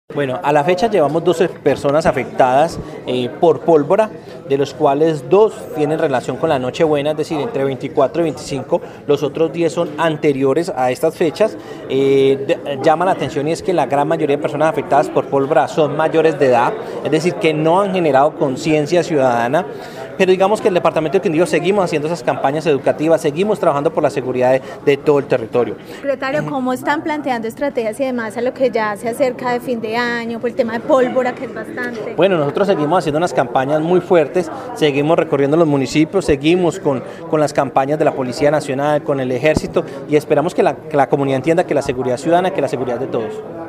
Secretario del Interior del Quindío.